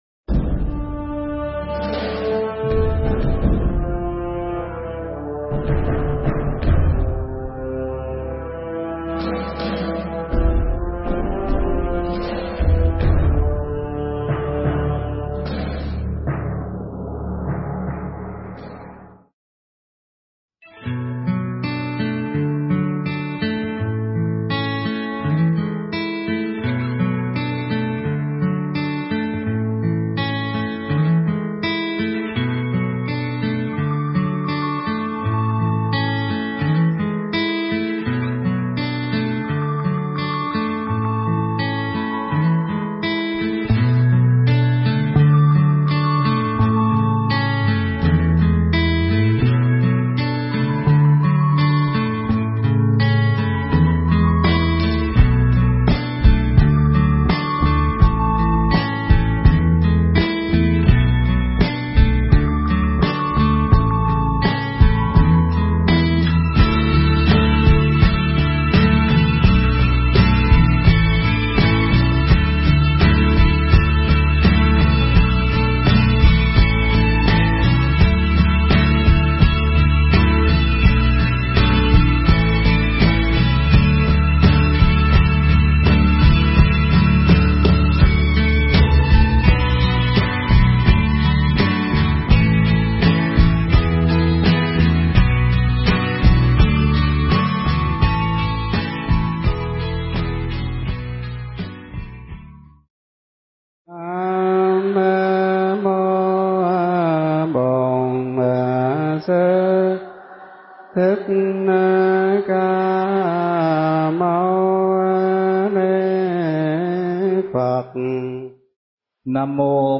MP3 Thuyết pháp Đạo Phật Và Tâm Linh